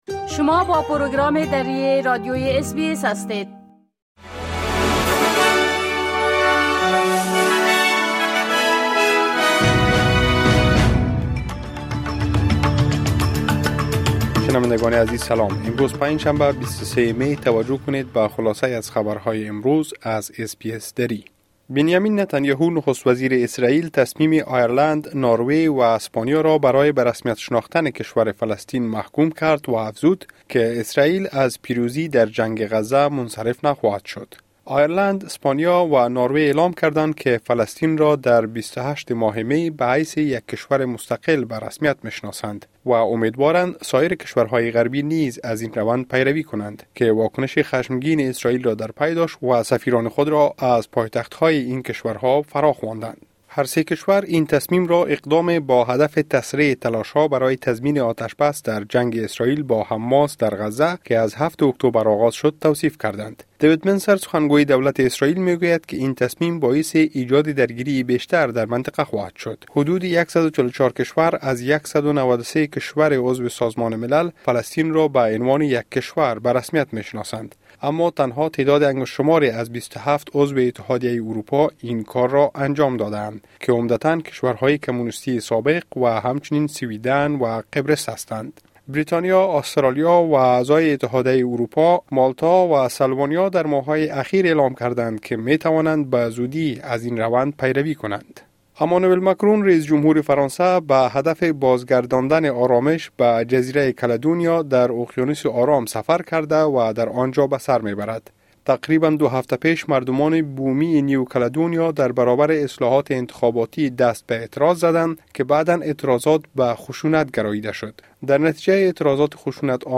خلاصۀ مهمترين اخبار روز از بخش درى راديوى اس بى اس|۲۳ می ۲۰۲۴